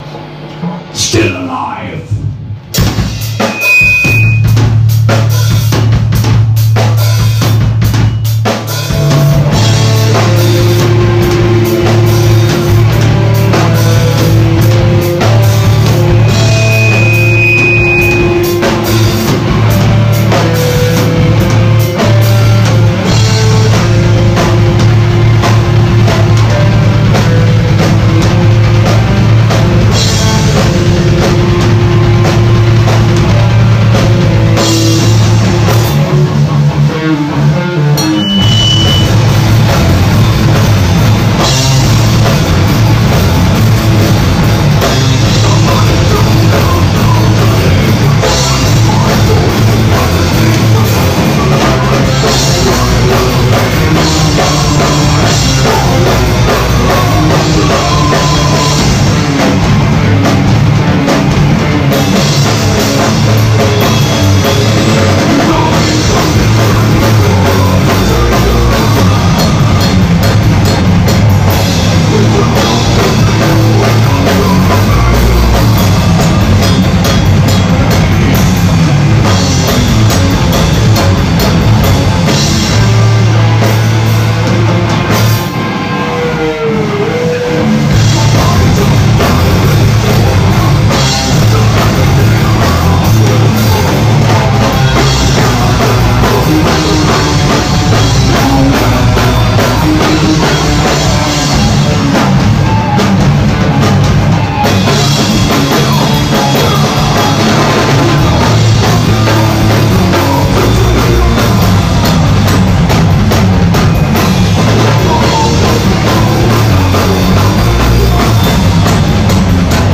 Y para poder celebrar con todo la banda accedió a una entrevista en la cual hablaron de sus inicios, de su cambio de formación y de los proyectos que se vienen, te dejamos la nota a NOT AS OTHERS: Entrevista a Not As Others por su 3er. Aniversario